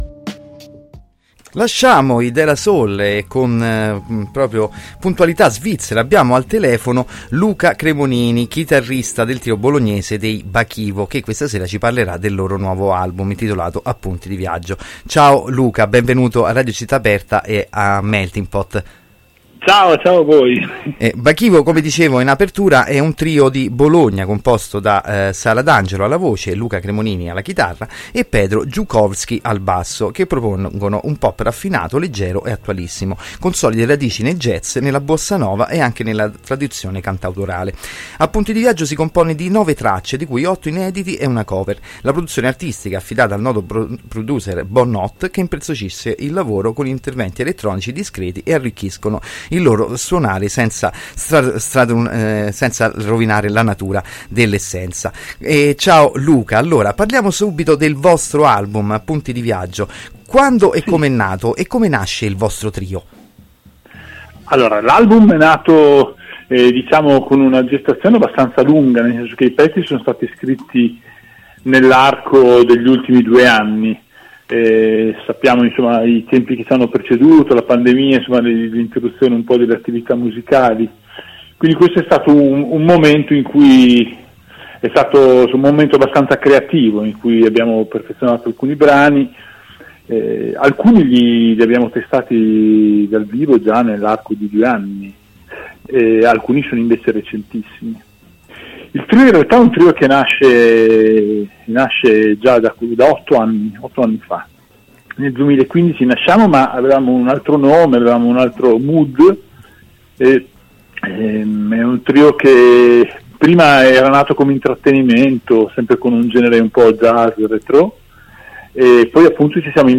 ci ha raggiunti al telefono